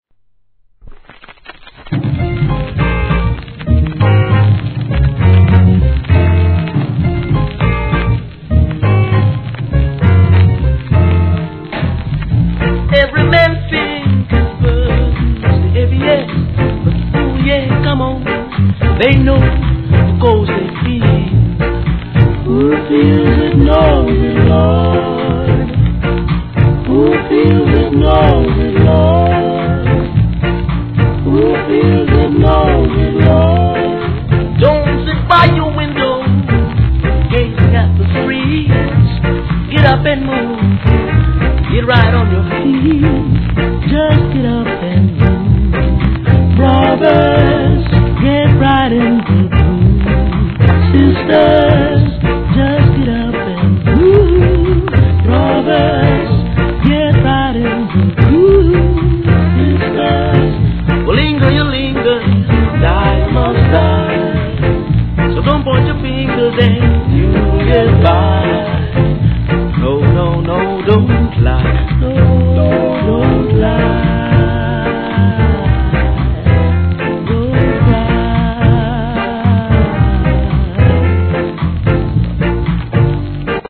C / 盤面に細かいスレ傷目立ち、チリ入りますがPLAY可能だと思います(SAMPLE確認ください)
1. REGGAE